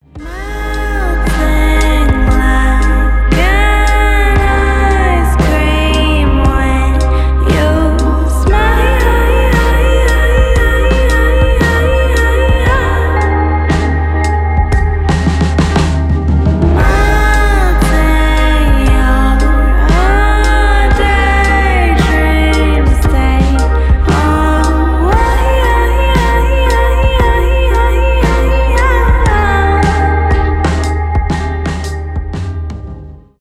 поп
соул
indie pop , красивый женский голос